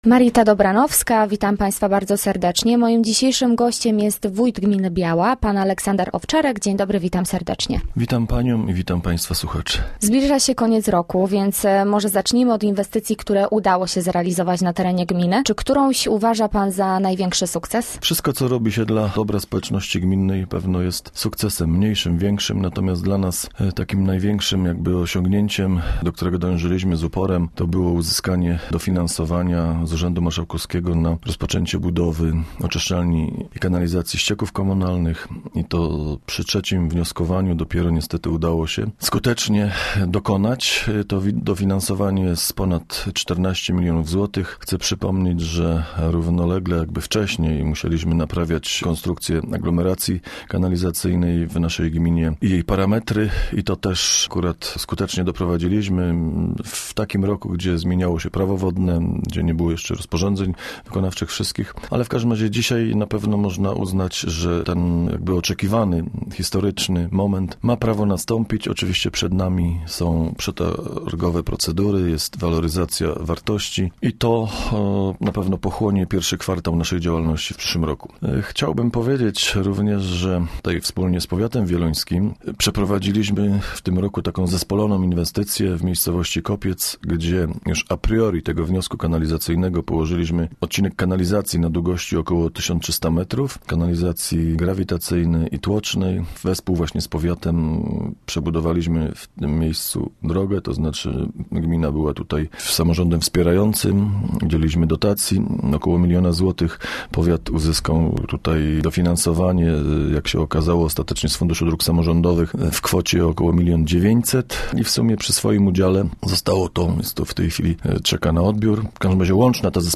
Gościem Radia ZW był wójt gminy Biała, Aleksander Owczarek